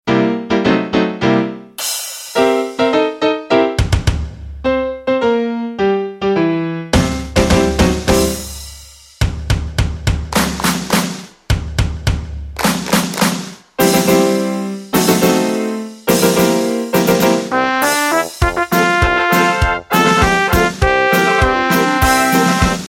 - Mp3 Instrumental Song Track